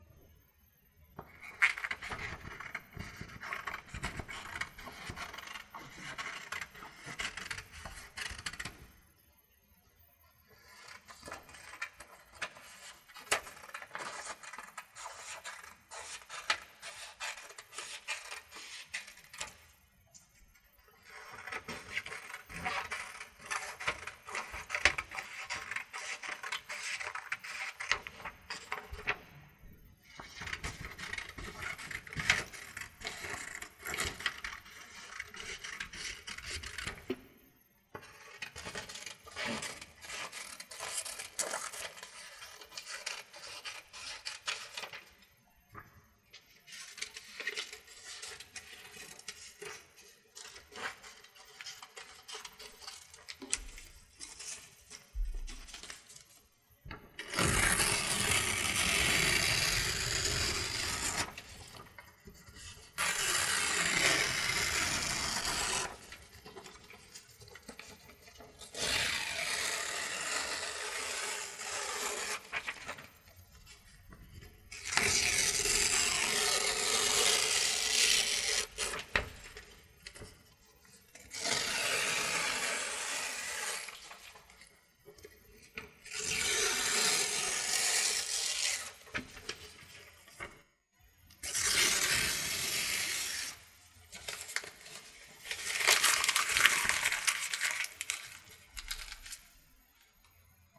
Возьмем лист бумаги, будем резать его ножницами и рвать, помещая перед микрофоном и по разные стороны от него.
Пример записи на микрофон (стерео, лист бумаги в 50 см)
Звук получился сравнительно естественный, нужная атмосфера создается, стереоэффект присутствует — любителям жанра может и понравиться, такой сценарий использования Thermaltake GS50 RGB тоже возможен.
Paper.mp3